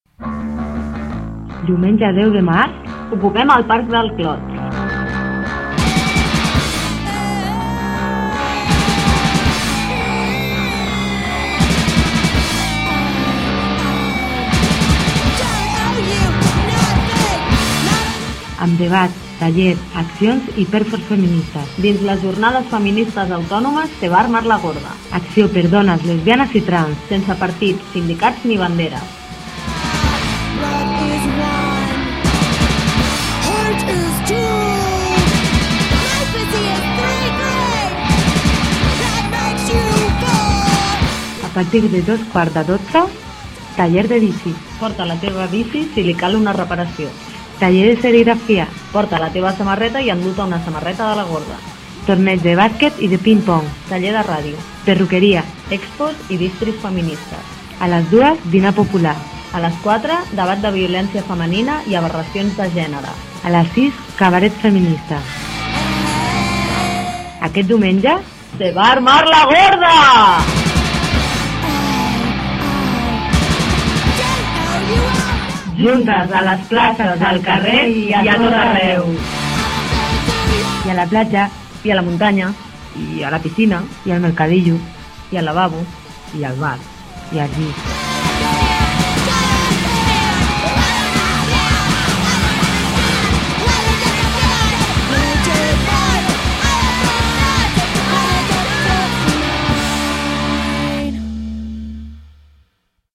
falques de ràdio: